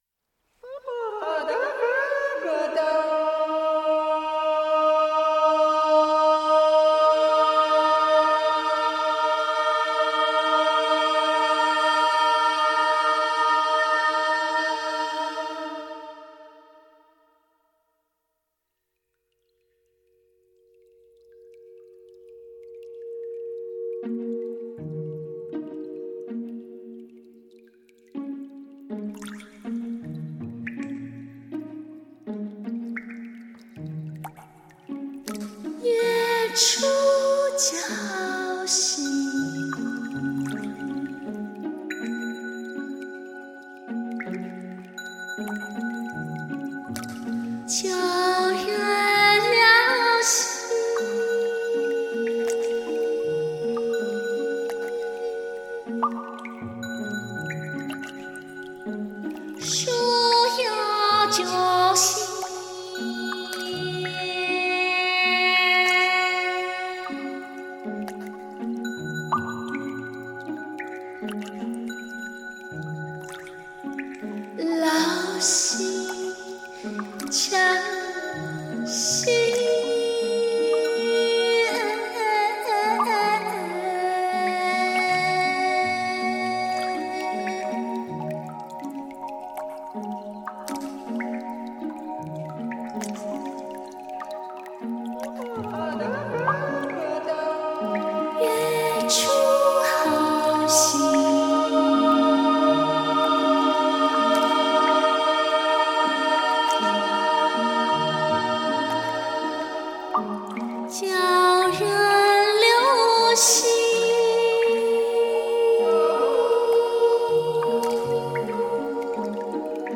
以突破性的创作观念融合当代电子等音乐语汇形式制作的体现中国当代精神、面向未来的独特“中国声音”。